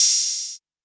Open Hats
Space OH.wav